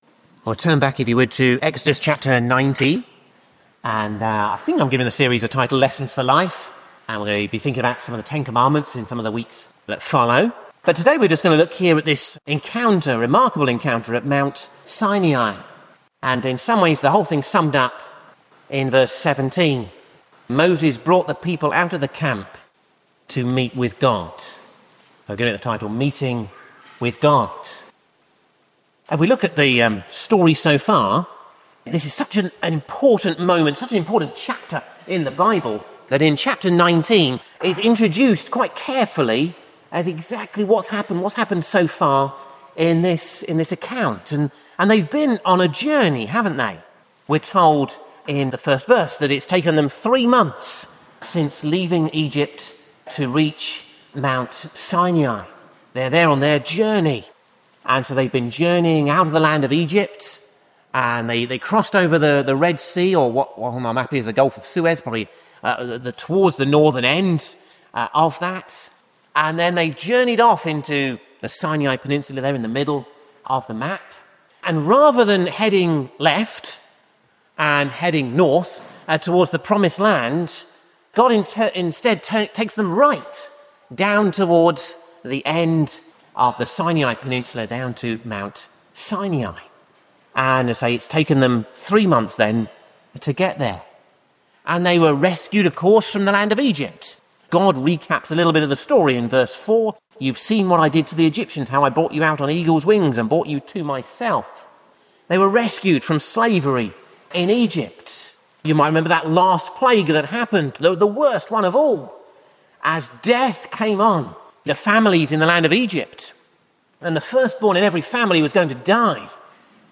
'Exodus: Lessons For Life' Sermon Series: Ashbourne Baptist Church 2026